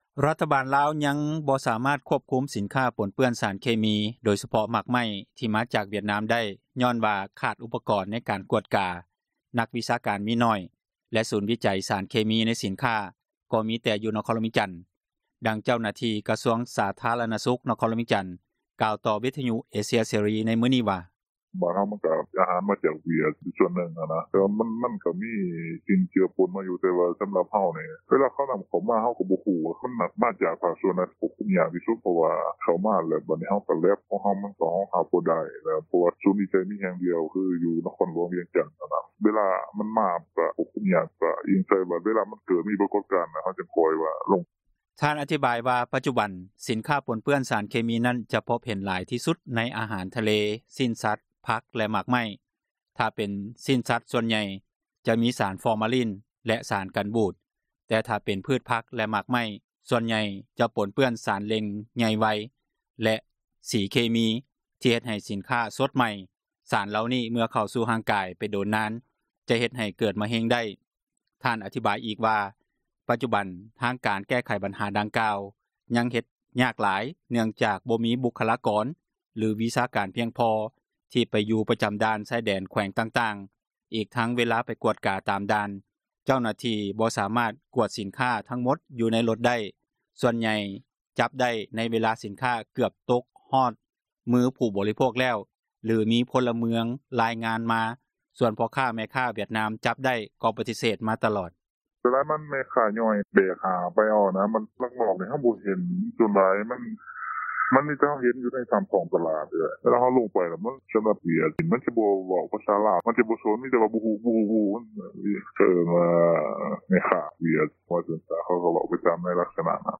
ຣັຖບານລາວ ຍັງບໍ່ສາມາດ ຄວບຄຸມສິນຄ້າ ປົນເປື້ອນສານເຄມີ ໂດຍສະເພາະ ໝາກໄມ້ ທີ່ມາຈາກວຽດນາມໄດ້ ຍ້ອນວ່າ ຂາດອຸປະກອນ ໃນການກວດກາ ນັກວິຊາການມີໜ້ອຍ ແລະ ສູນວິໃຈສານເຄມີ ໃນສິນຄ້າ ກໍມີ ແຕ່ຢູ່ນະຄອນຫລວງວຽງຈັນ, ດັ່ງເຈົ້າໜ້າທີ່ ກະຊວງສາທາຣະນະສຸຂ ນະຄອນຫຼວງວຽງຈັນ  ກ່າວຕໍ່ ວິທຍຸ ເອເຊັຽ ເສຣີ ໃນມື້ນີ້ວ່າ: